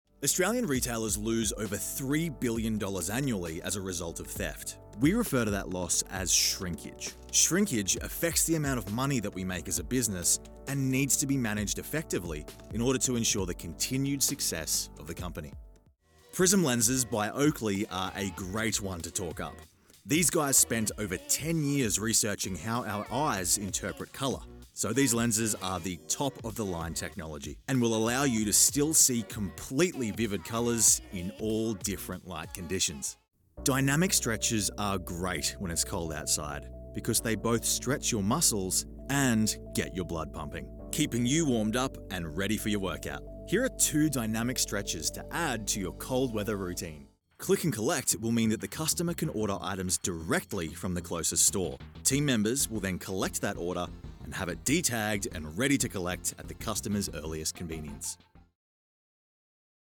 Male
English (Australian)
E-Learning
Studio Quality Sample
0820E-Learning_Reel.mp3